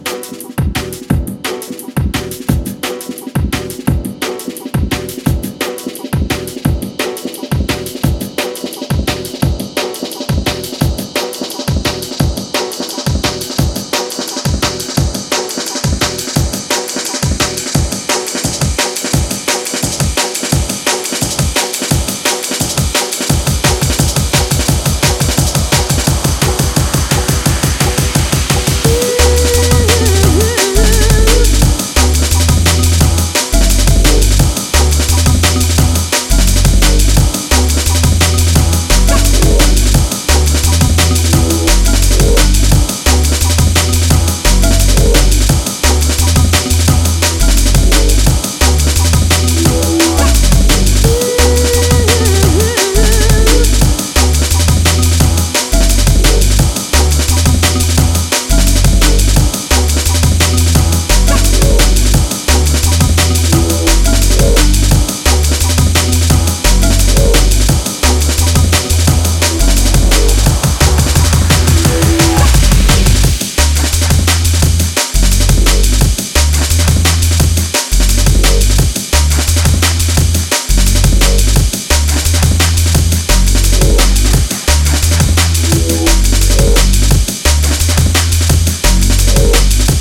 Drum and Bass / Jungle